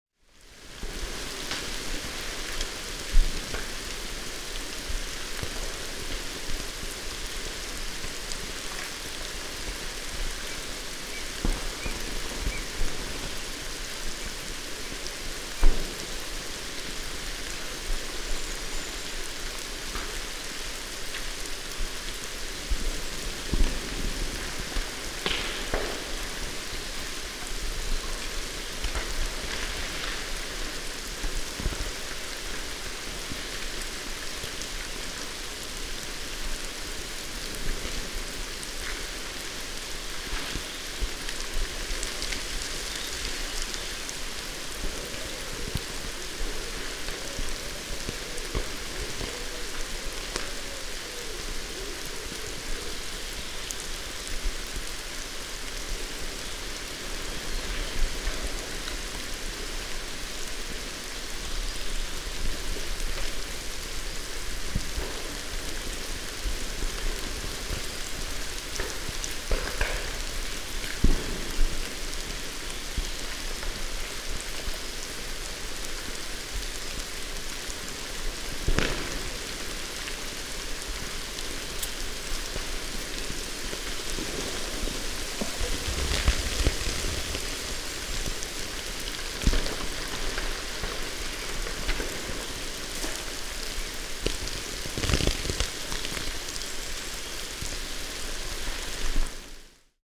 Stueleggwald. Erinnerung an den letzten Frühlingsschnee:
tau-stueleggwald_2.mp3